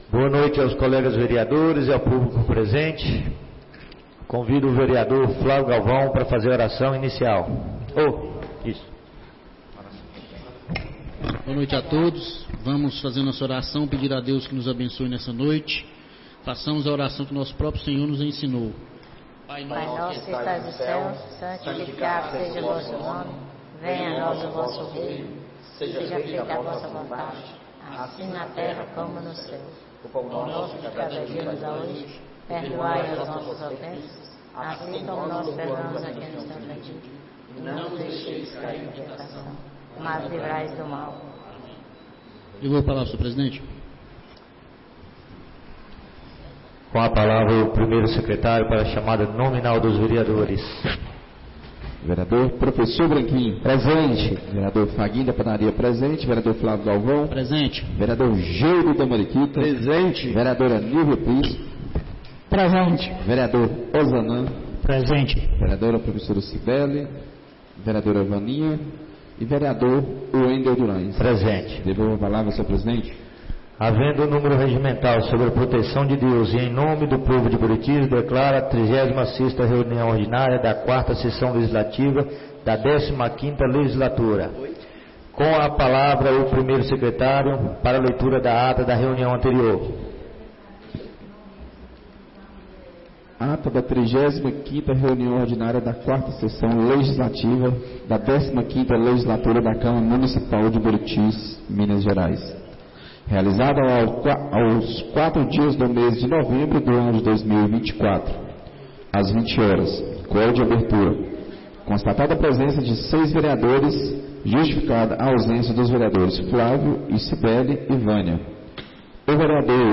36ª Reunião Ordinária da 4ª Sessão Legislativa da 15ª Legislatura - 11-11-24